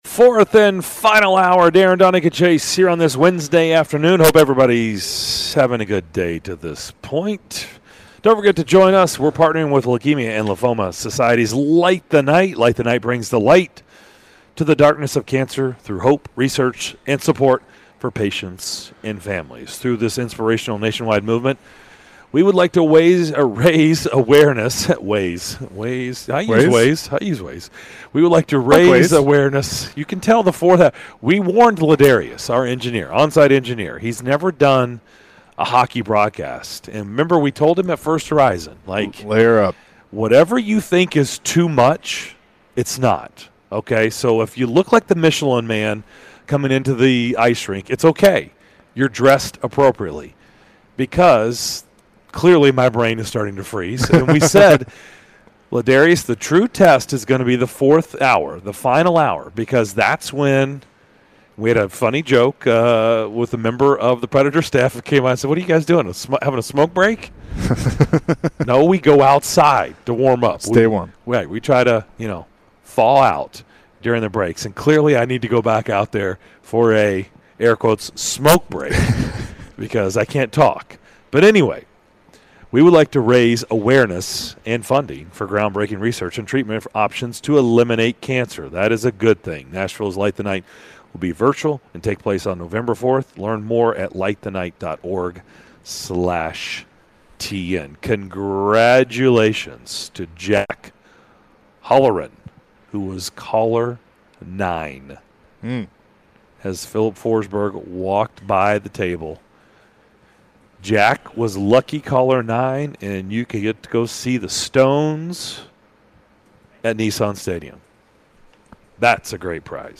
Nashville Predators Head Coach John Hynes joined the DDC to discuss the state of his team going into the 2021-2022 season!